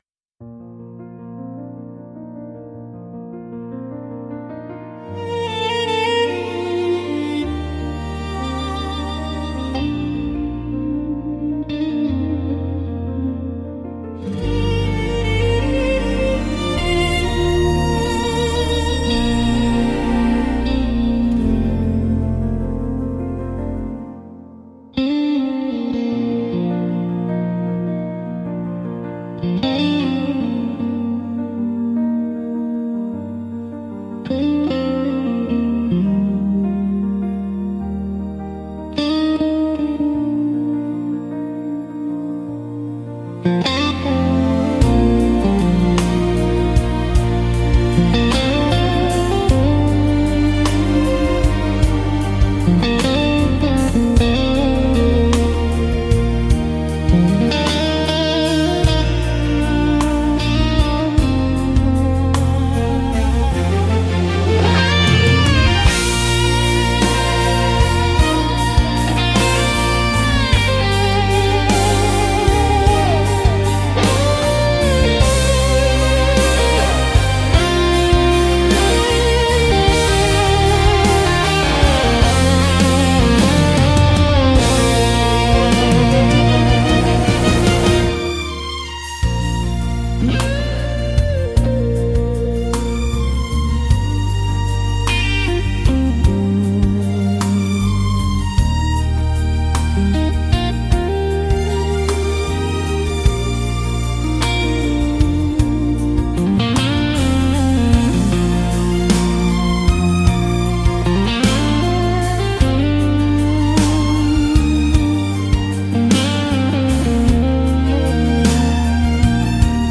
聴く人に新鮮な発見と共感をもたらし、広がりのある壮大な空間を演出するジャンルです。